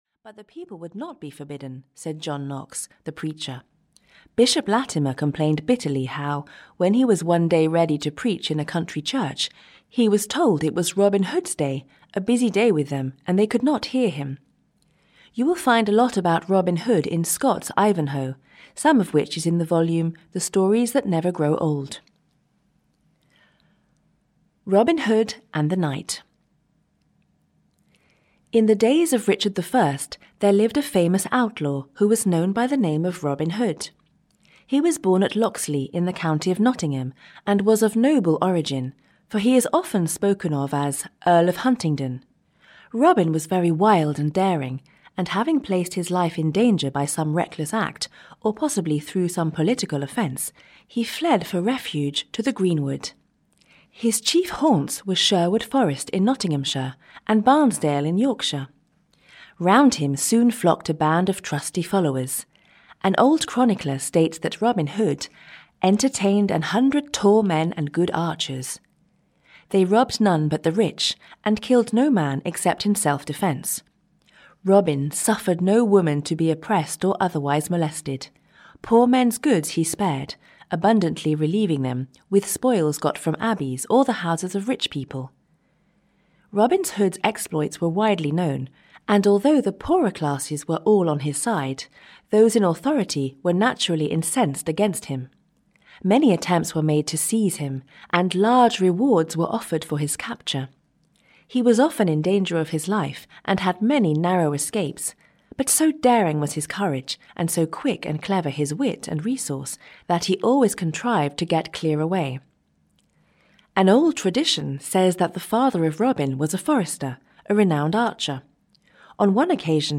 Tales of Robin Hood (EN) audiokniha
Ukázka z knihy